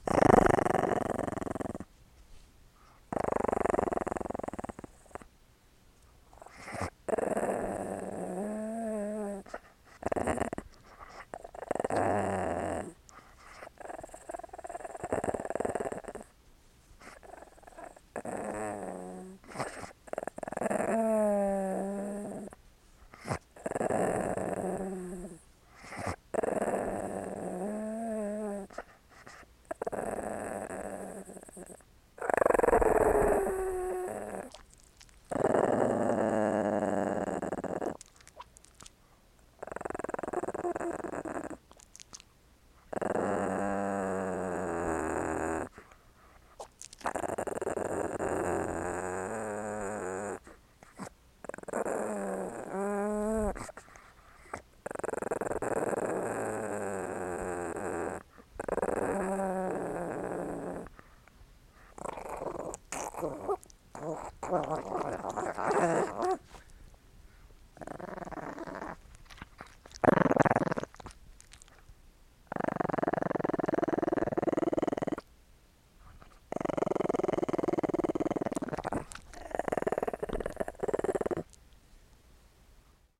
dog-sound